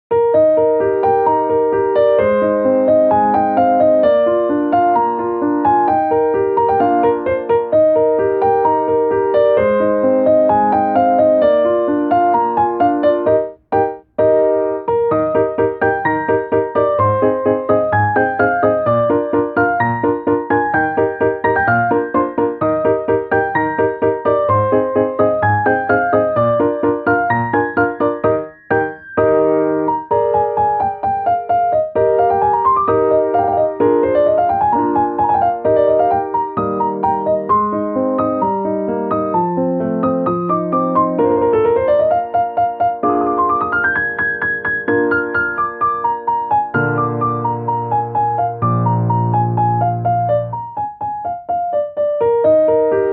ogg(L) - 爽快 明るい 解放感